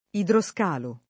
DOP: Dizionario di Ortografia e Pronunzia della lingua italiana
[ idro S k # lo ]